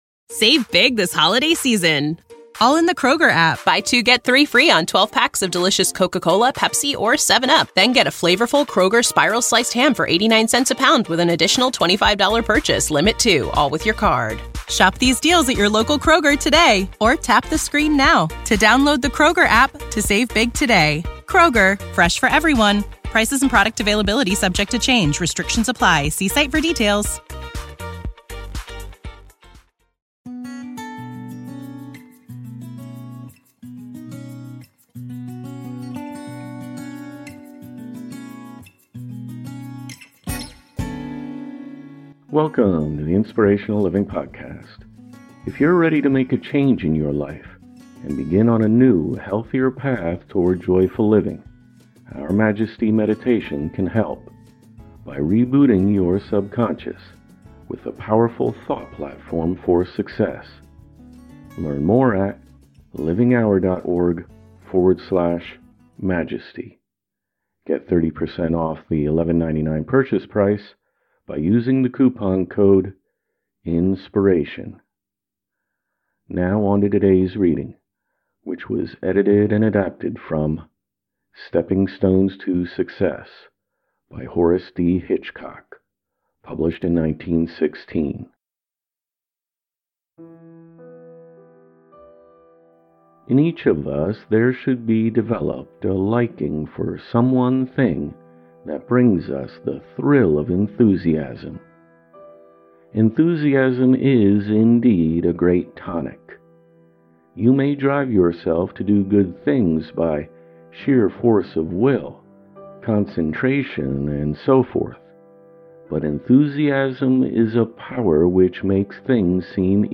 Look no further than the most motivational self-help authors of the past. Inspiring readings from James Allen, Napoleon Hill, Hellen Keller, Booker T. Washington, Khalil Gibran, Marcus Aurelius, and more.